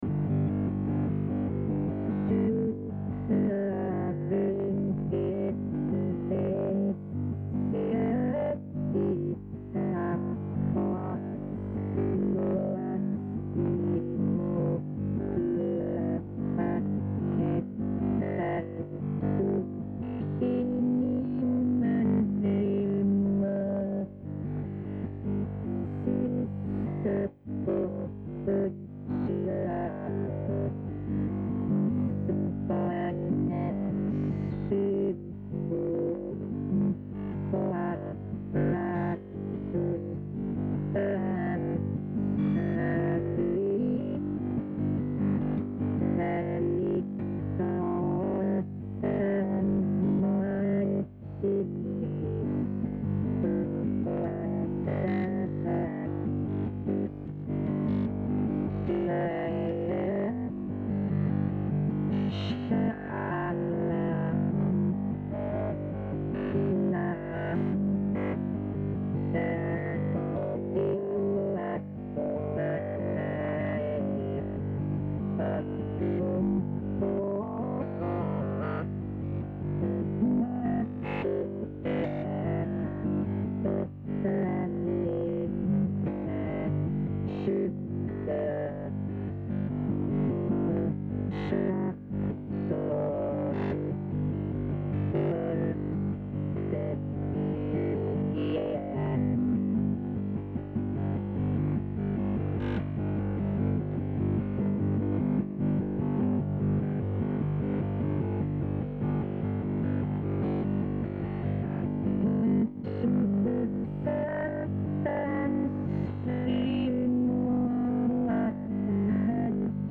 Recorded in Kuala Lumpur